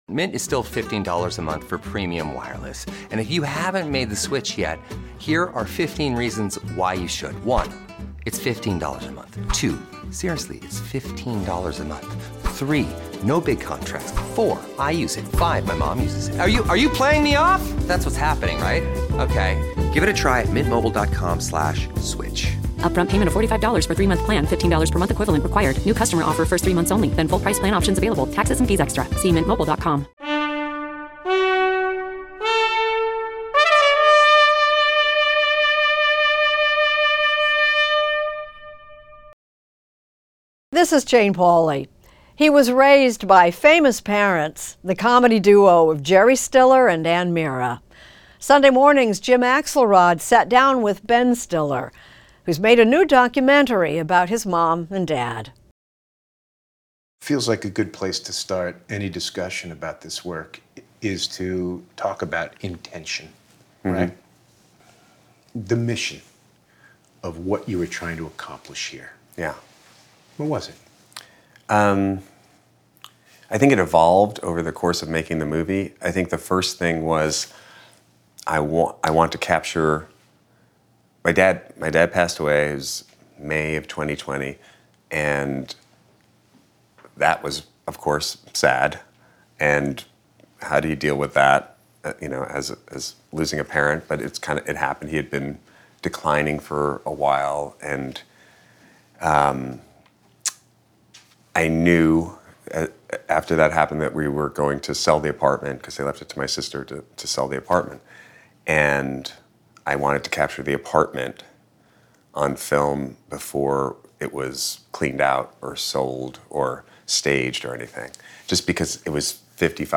Extended Interview: Ben Stiller Podcast with Jane Pauley